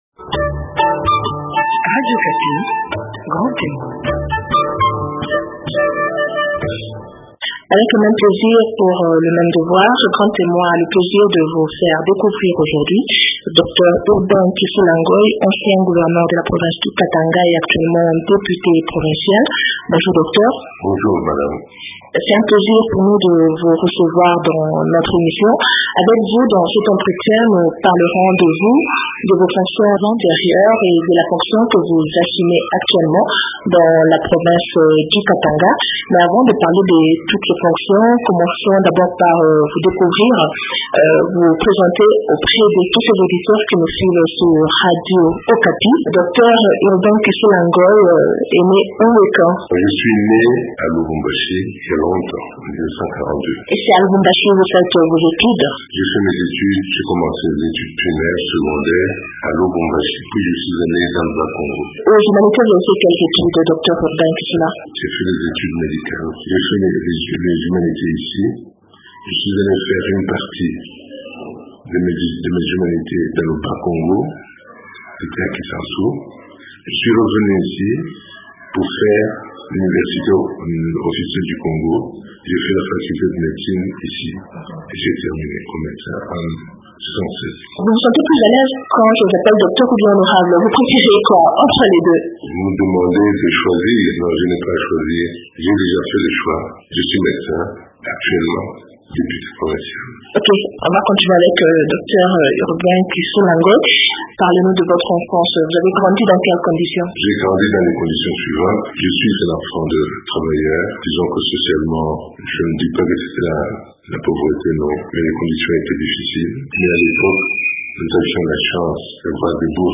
Docteur Urbain Kisula Ngoie est ancien gouverneur de la province du Katanga .Il est actuellement député provincial dans la même province